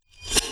Shield Damage Absorption.wav